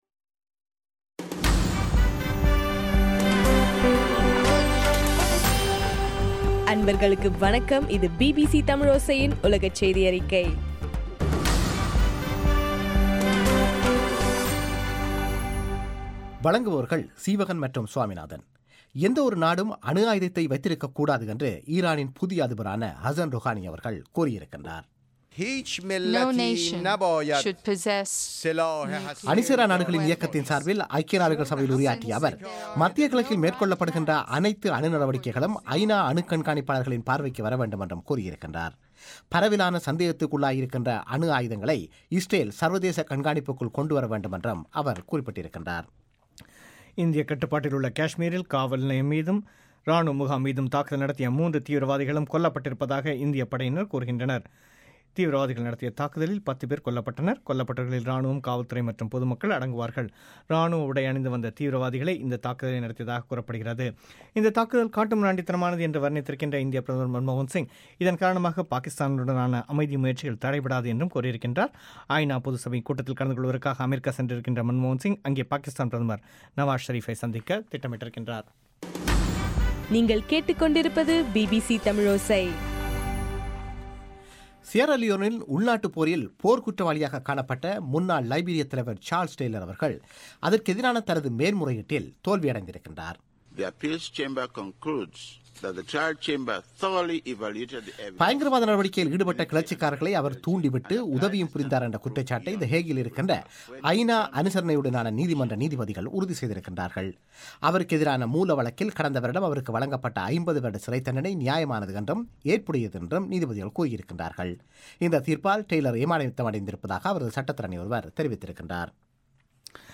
செப்டம்பர் 26 பிபிசியின் உலகச் செய்திகள்